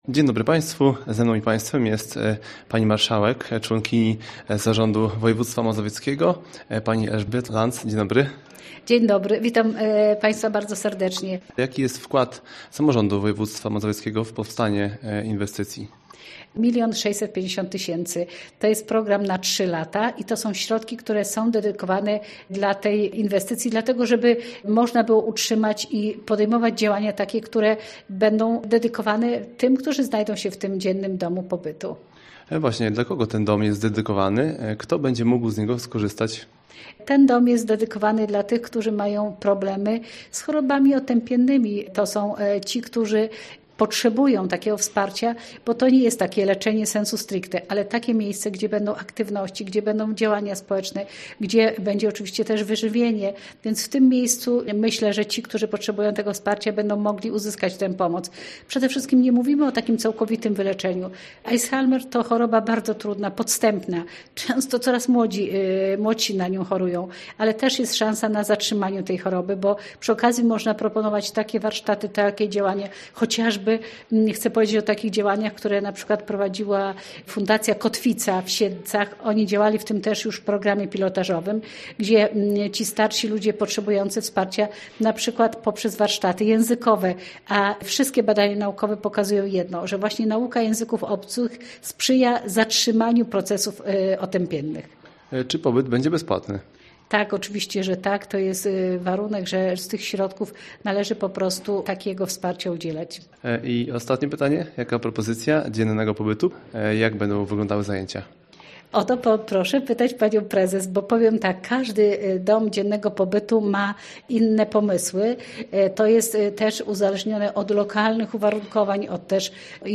Cała rozmowa z członkinią zarządu województwa mazowieckiego, Elżbietą Lanc oraz fotorelacja ze spotkania dostępna jest poniżej.